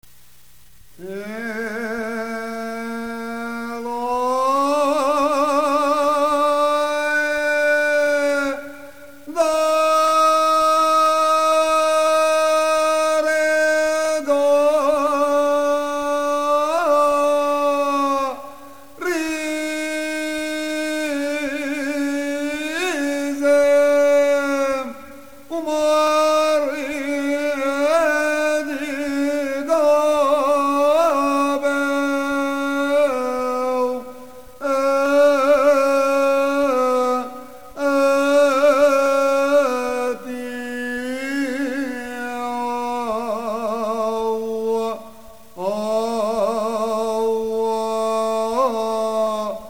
Monofónica la llaman los conocedores; monótona la llamaría yo.
Son cantos a cappella, la mayoría de ellos sin acompañamiento instrumental alguno. Por lo general, organizados en 24 stanzas de 4 versos cada una, son interpretados por grupos de cantantes -los de derecha y los de izquierda-, divididos en dos grupos que se alternan de a 2 versos.